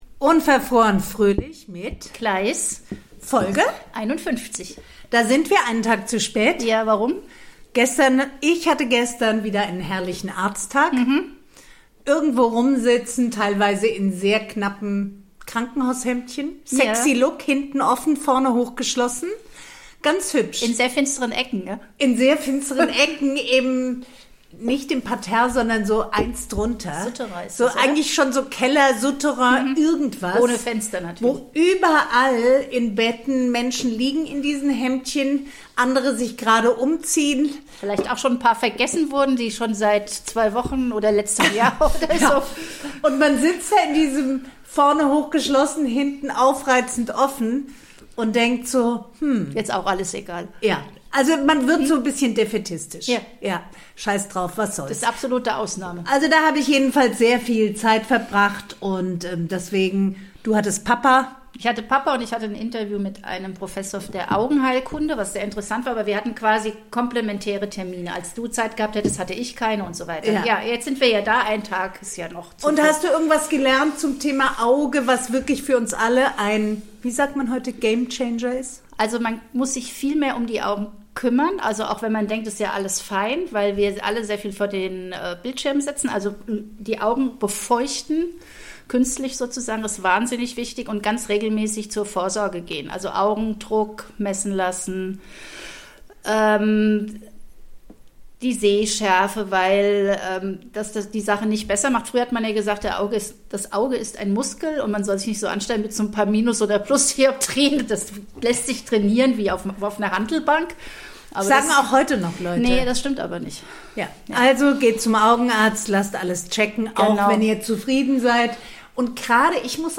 reden die beiden Podcasterinnen über Tipps fürs Auge, über den fiesen Nikotinentzug und Haut, die eben nicht mehr Dreißig ist.